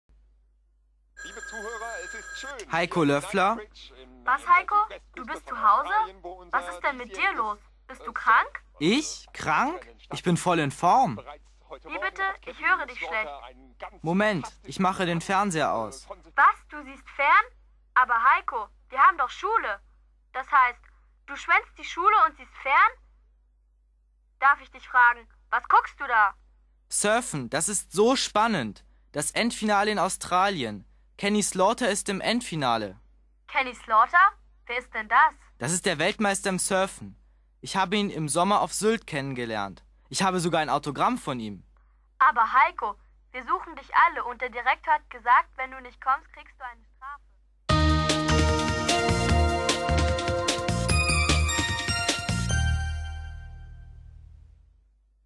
- Alles klar? Complète le dialogue entre le principal, Heiko et ses parents à l'aide du fichier son.